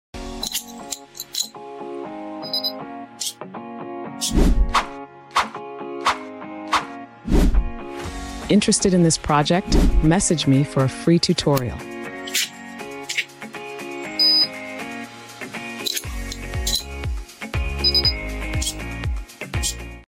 RFID Access Control With Servo Sound Effects Free Download